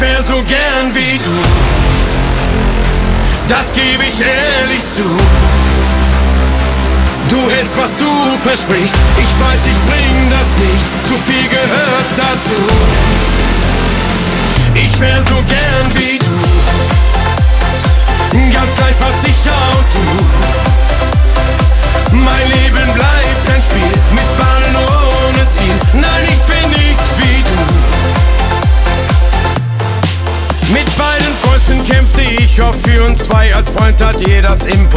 Жанр: Поп
# German Pop